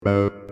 Sequencial Circuits - Prophet 600 33